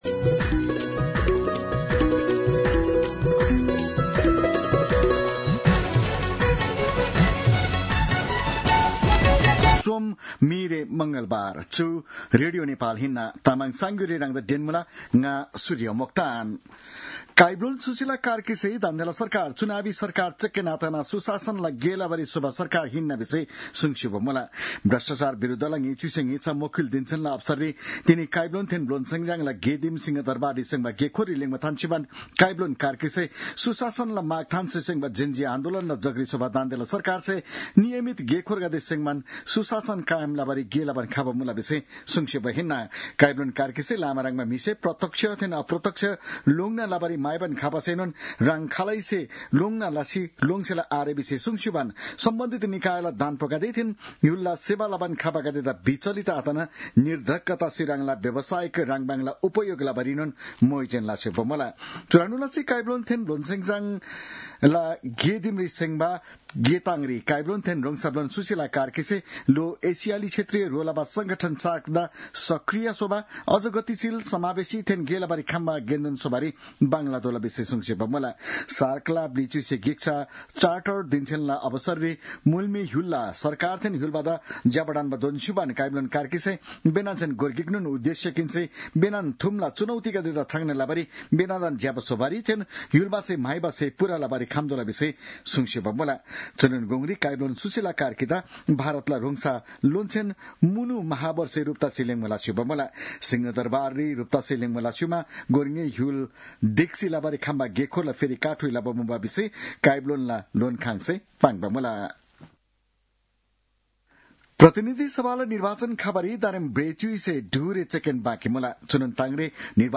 तामाङ भाषाको समाचार : २३ मंसिर , २०८२